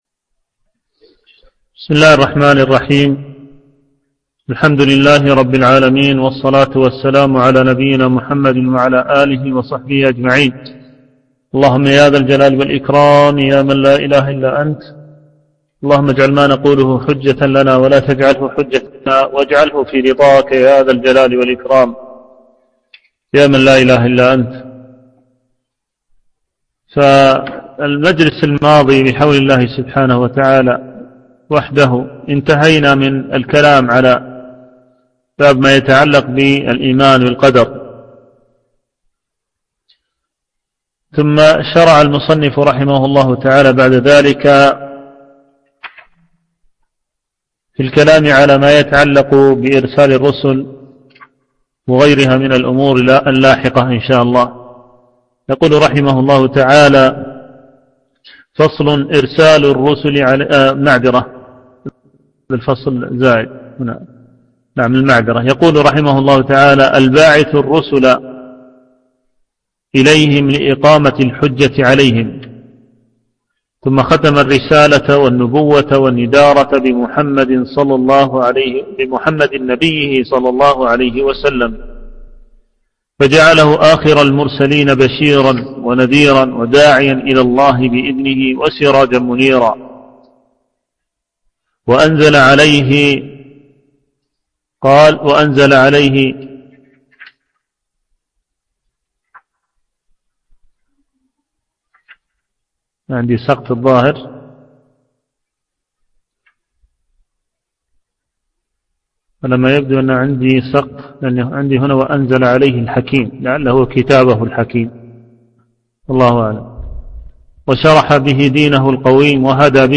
شرح مقدمة رسالة ابن أبي زيد القيرواني - الدرس الرابع عشر